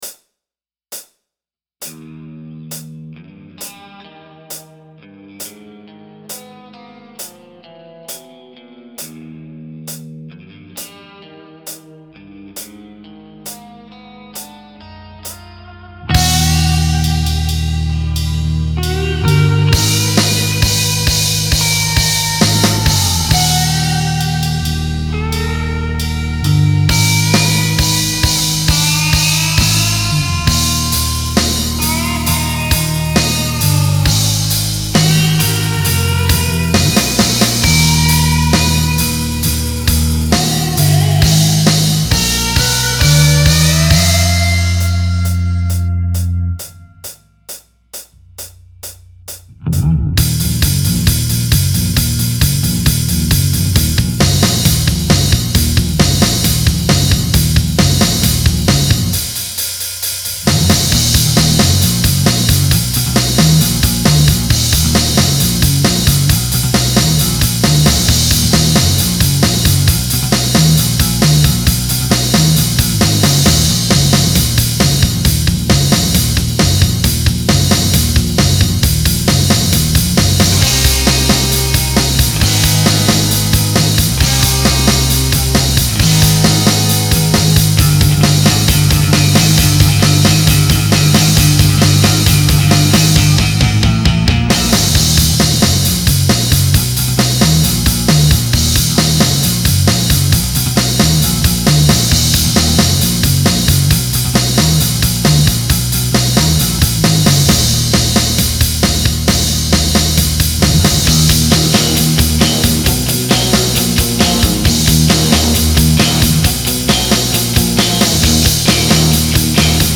You be the main guitar part
*TIP:  The song begins with a 2 count on the hi-hat.